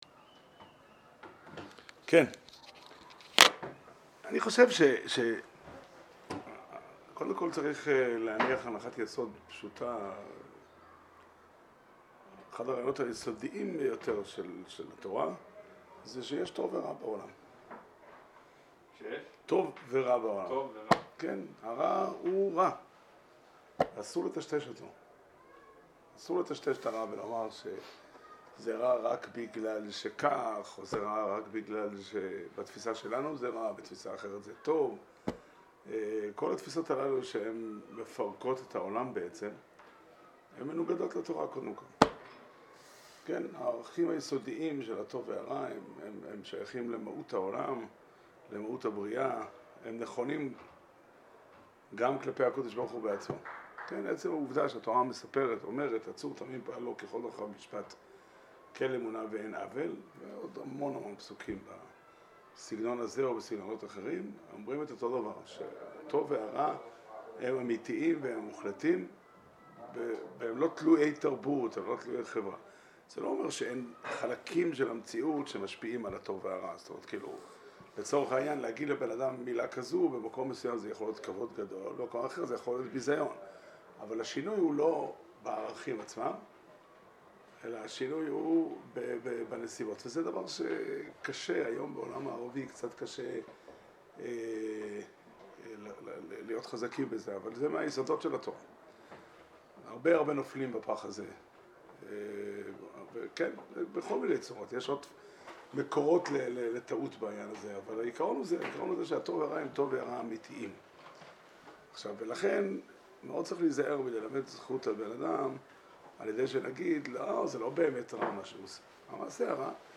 שיעור שנמסר בבית המדרש 'פתחי עולם' בתאריך ד' כסלו תשפ"ב